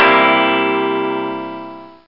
Guitar Sound Effect
guitar.mp3